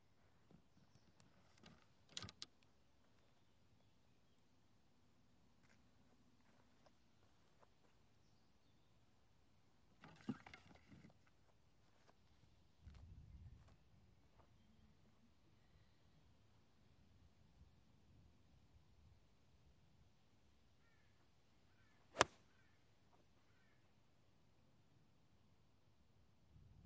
体育 " HSN高尔夫铁杆七
描述：高尔夫用Yonex的铁七打。
标签： 挥杆 高尔夫 体育
声道立体声